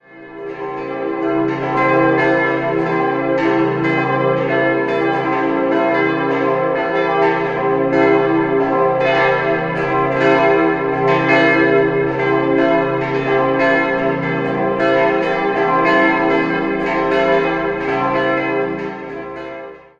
Der Stuck wird Johann Baptist Modler oder Franz Josef Holzinger zugeschrieben. 4-stimmiges Geläut: c'-e'-g'-a' Die drei größeren Glocken wurden 1950 von Rudolf Perner in Passau gegossen, die kleine Glocke stammt noch aus dem Jahr 1695.